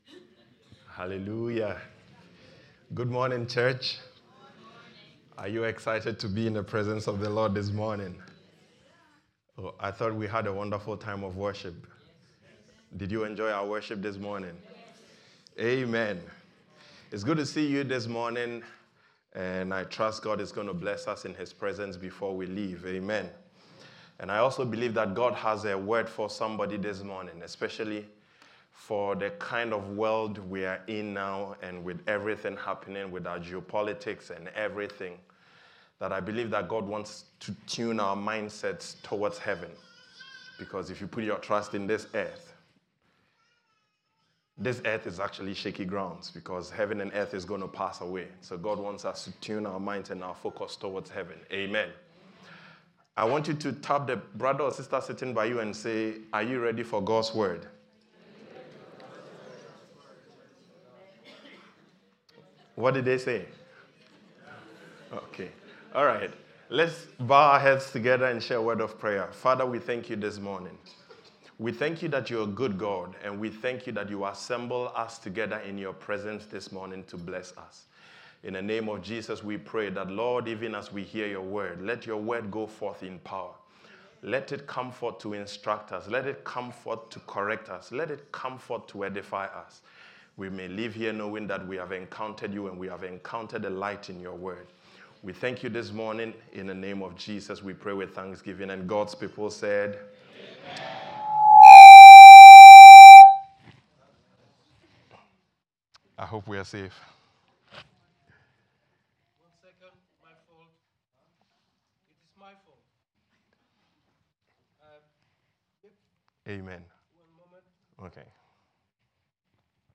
Sermons – Maranatha Community Church